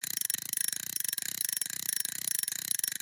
reel.mp3